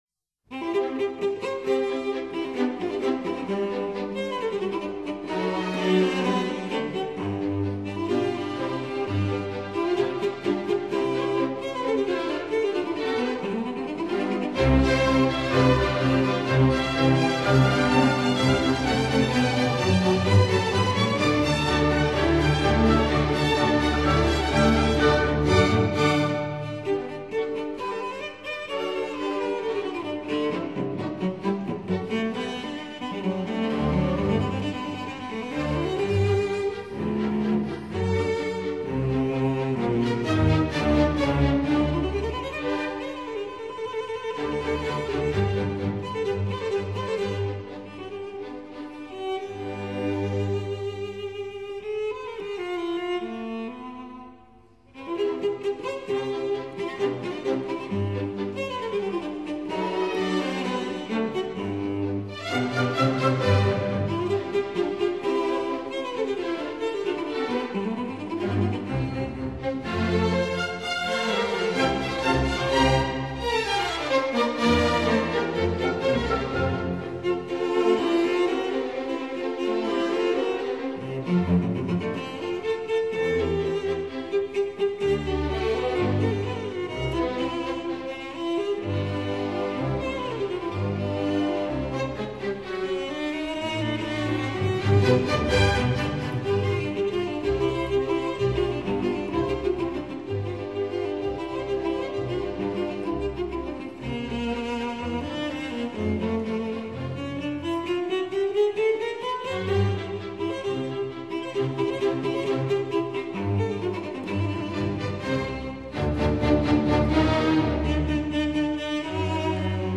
Concerto in C major for Cello and Orchestra